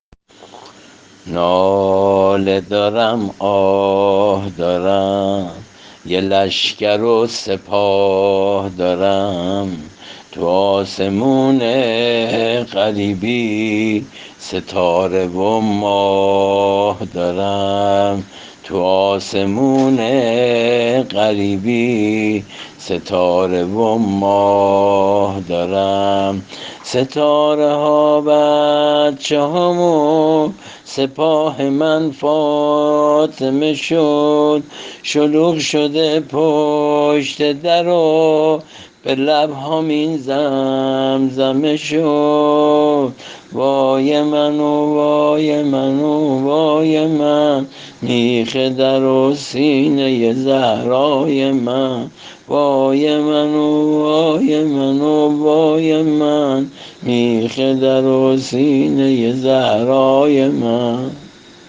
عنوان : متن و سبک سنتی سینه زنی فاطمیه